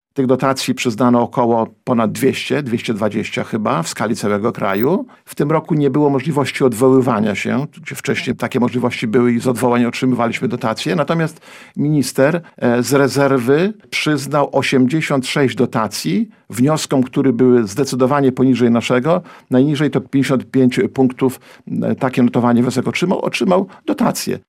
To jest skandaliczne – tak na antenie Radia Nadzieja całą sytuację skomentował Starosta Łomżyński: